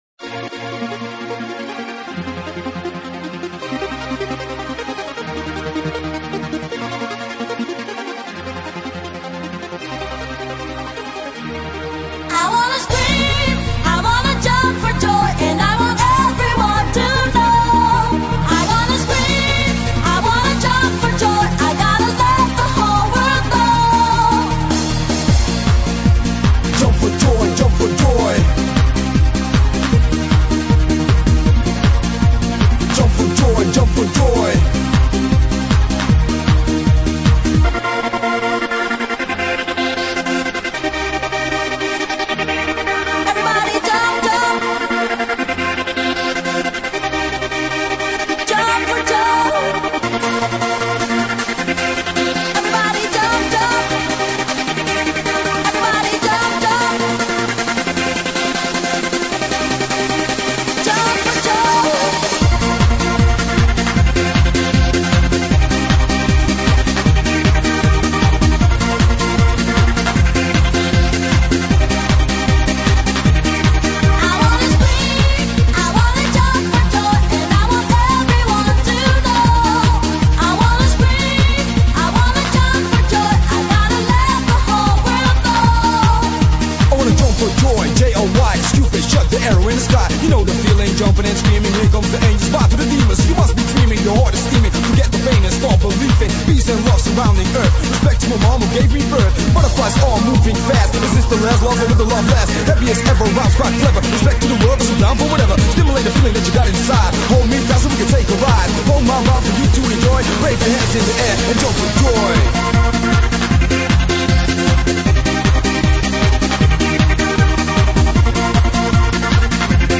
Назад в Exclusive EuroDance 90-х